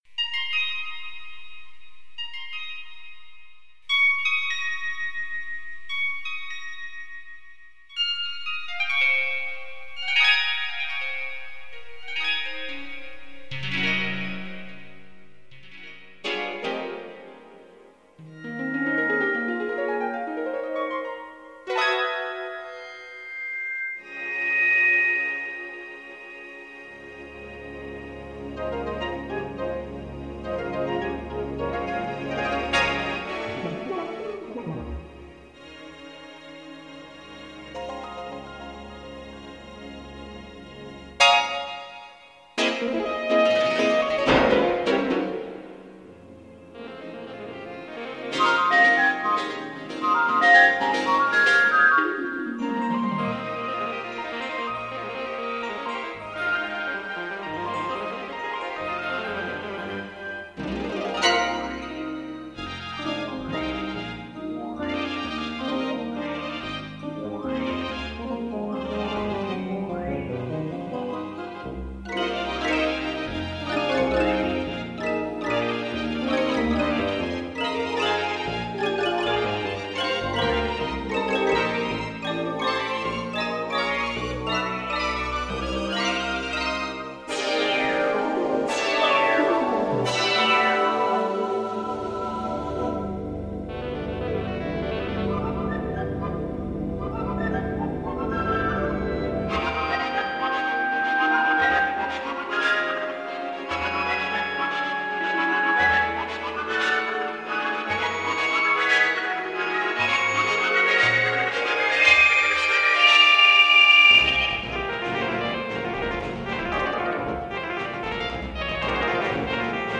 for Electronic music, Video, Laser, Performance and more
現在の音楽制作現場で行われている様な、DAWや、パソコン上で動くソフトウェアシンセサイザーでは無く、複数のシンセサイザー等をMIDIで繋ぎ、専用のシーケンサで制作する方法が主流で、シーケンサ上ではオーディオを扱っていない時代です。
なお、残っていた音源がプラネタリウム公演用で、ダイナミクスの幅が広かったものは、若干のコンプ処理をしてあります。